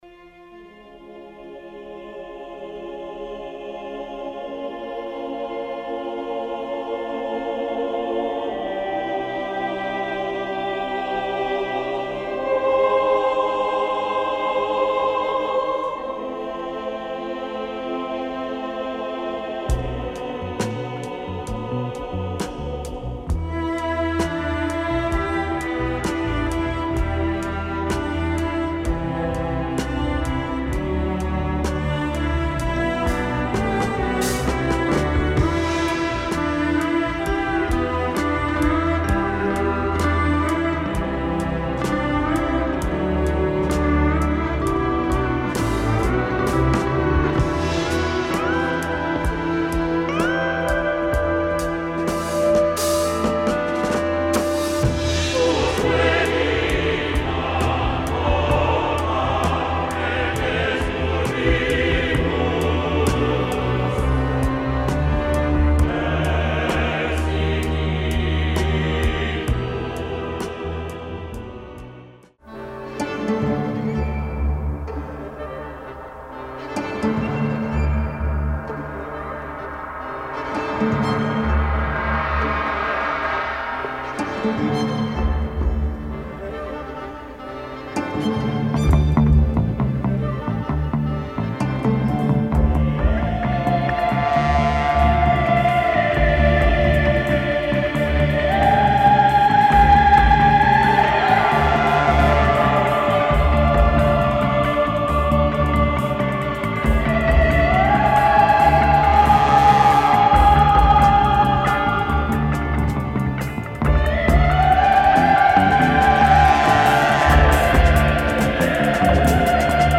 has a dope slow groove with breaks.